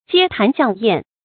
街談巷諺 注音： ㄐㄧㄝ ㄊㄢˊ ㄒㄧㄤˋ ㄧㄢˋ 讀音讀法： 意思解釋： 見「街談巷議」。